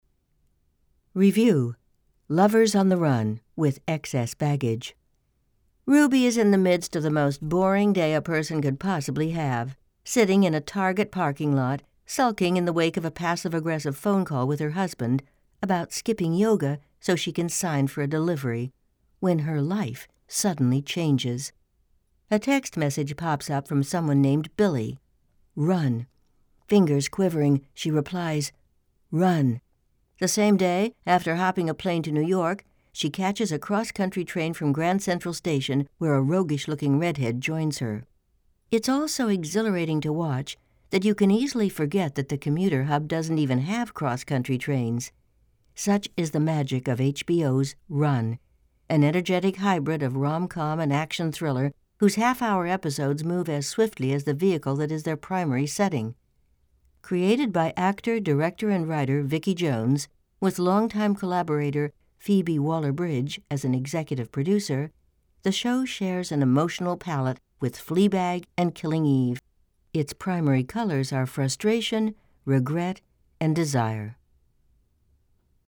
Voicereel
HOME STUDIO RECORDINGS